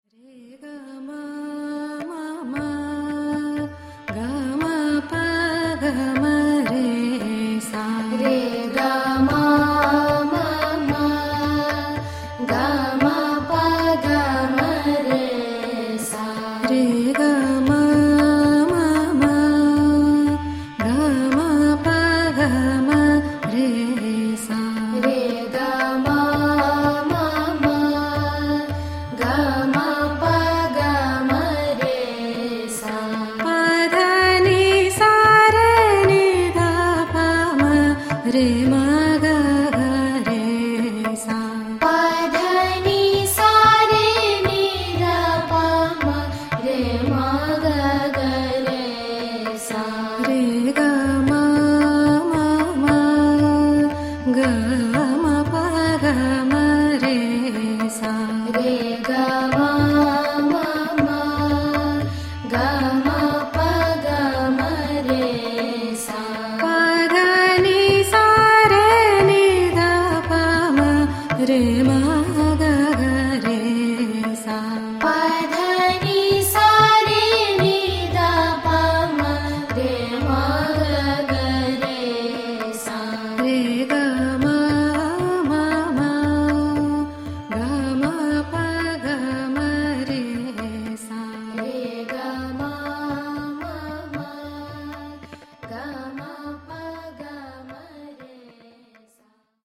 North-Indian classical vocal music traditions
contralto voice
Khayãl
live excerpt